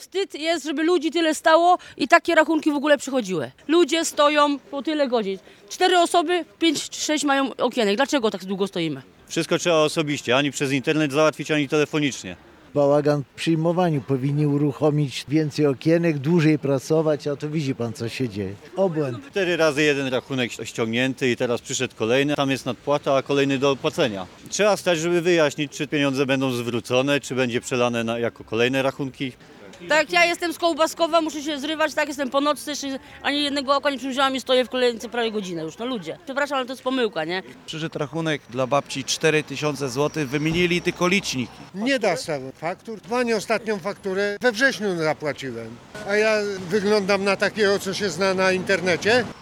Przed drzwiami Punktu Obsługi w długiej kolejce, na dworze, deszczu i zimnie oczekiwało kilkadziesiąt osób na załatwienie swojej sprawy.
– Zaznacza mężczyzna.